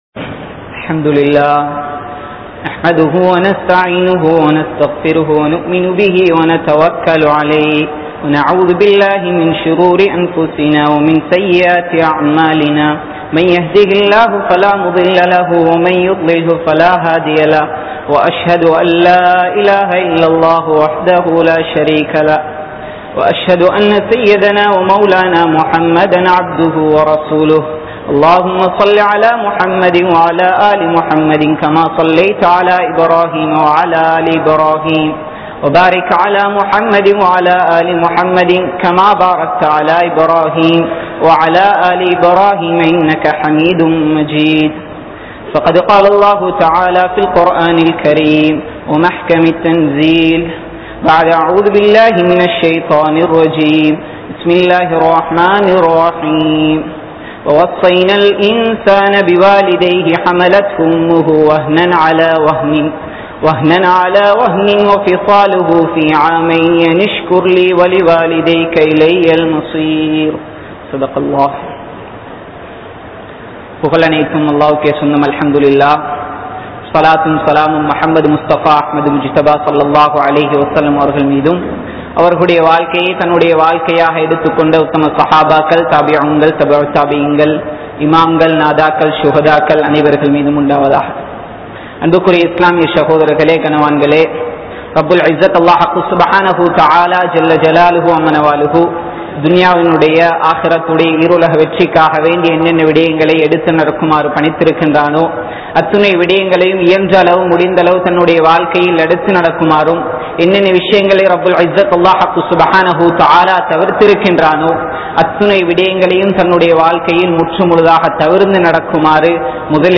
Petroarhalai Mathiungal | Audio Bayans | All Ceylon Muslim Youth Community | Addalaichenai